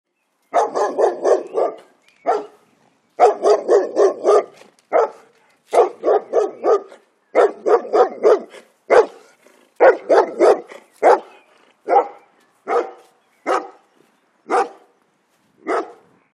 dog-dataset
dogs_0030.wav